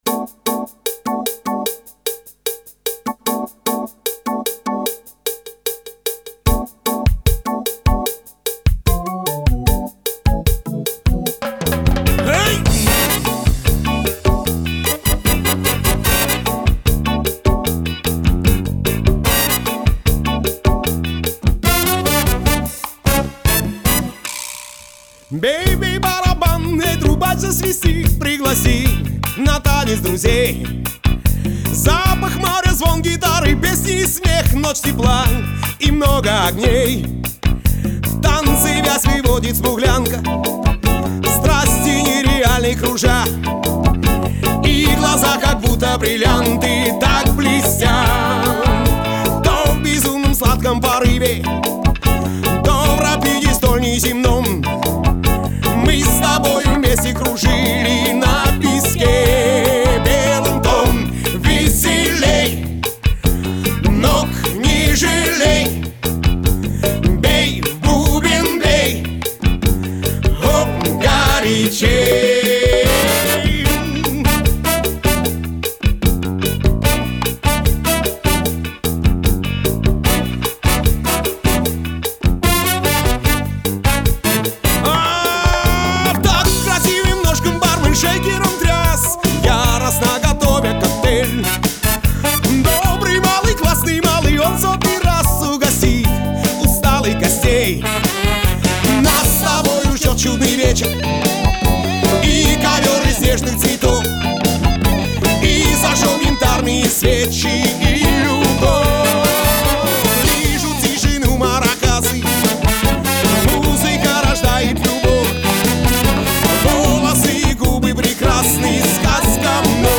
Хоп, веселей ! (latin cha-cha)
Ну я и переаранжировал и свёл.
Сейчас - 123. Вокал (будет переписываться) чуть утоплен, пока это просто демка.